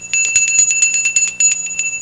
Dinnerbell Sound Effect
Download a high-quality dinnerbell sound effect.
dinnerbell.mp3